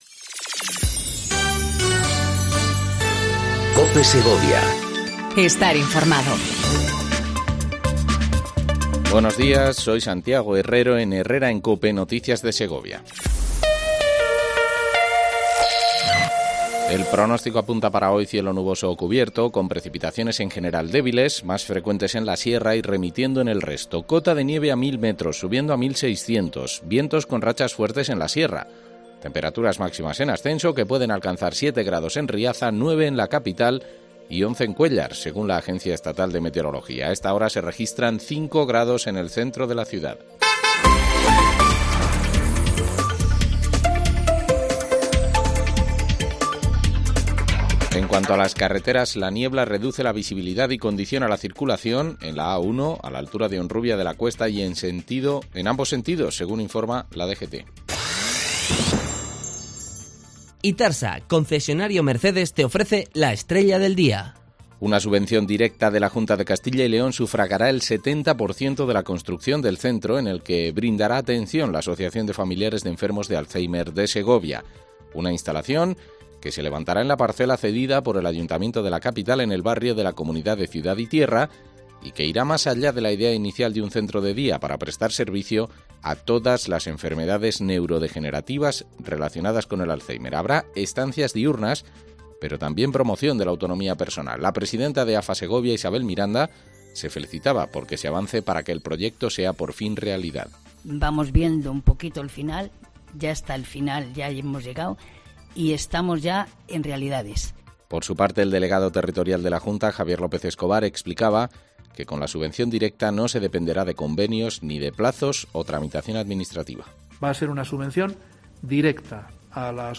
AUDIO: Segundo informativo local en cope segovia